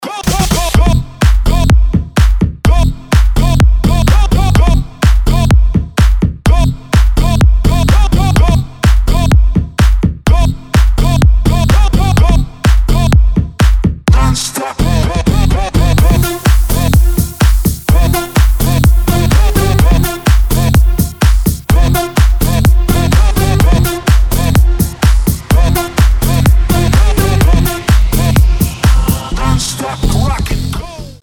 • Качество: 320, Stereo
ритмичные
громкие
progressive house
electro house